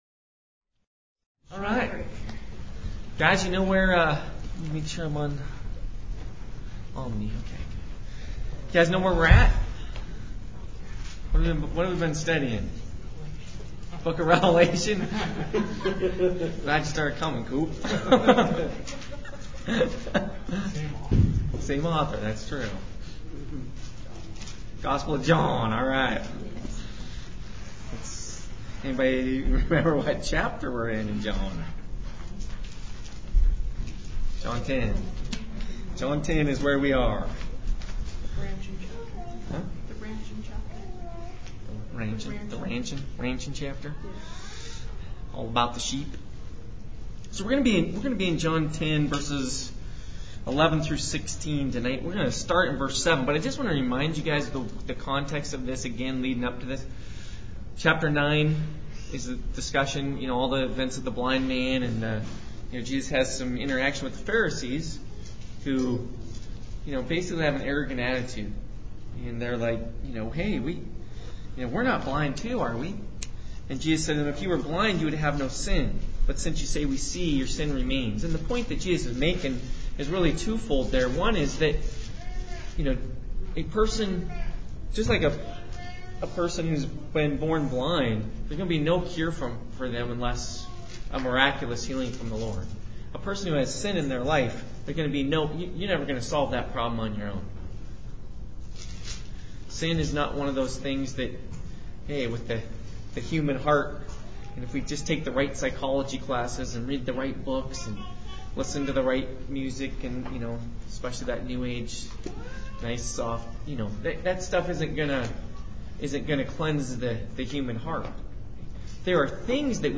during small group sessions.